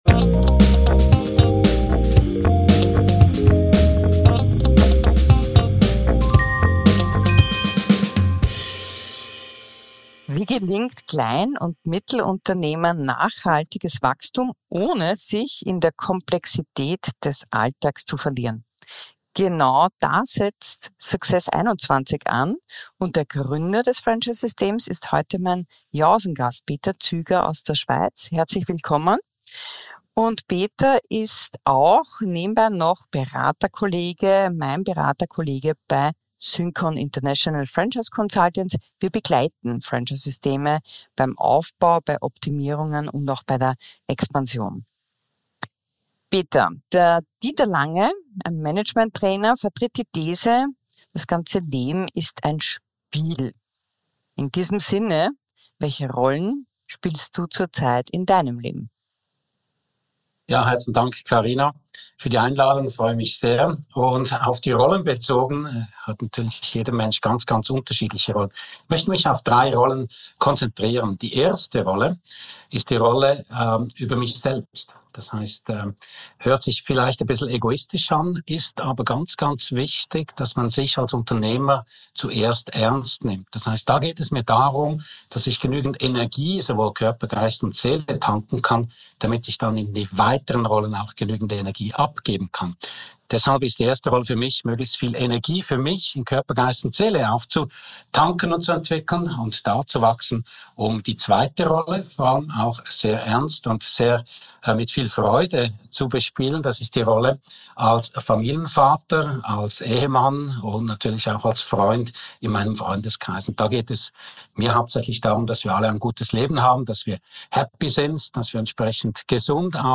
Ein Gespräch über Personal Development, Hamsterrad, Growth Mindset, Empfehlungsquote und Kundenerfolg